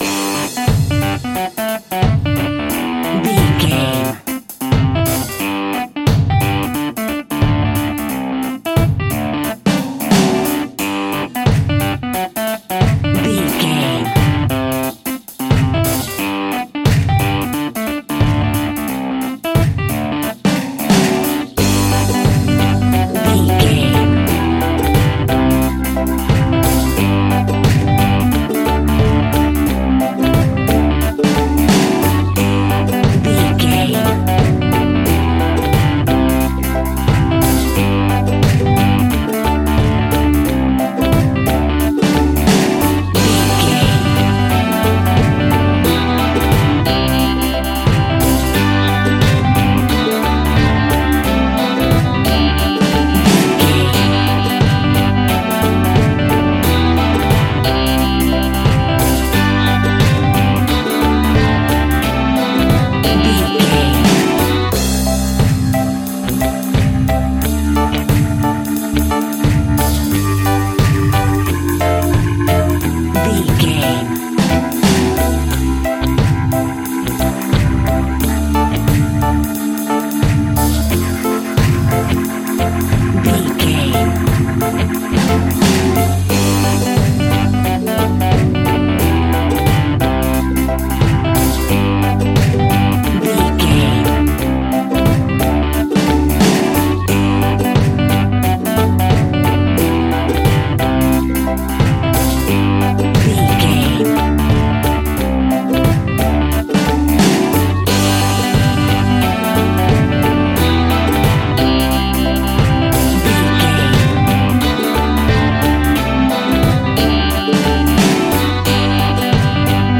Hot summer reggae music from Barbados!
Ionian/Major
dub
reggae instrumentals
laid back
chilled
off beat
drums
skank guitar
hammond organ
transistor guitar
percussion
horns